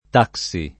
taxi [ t # k S i ] → tassì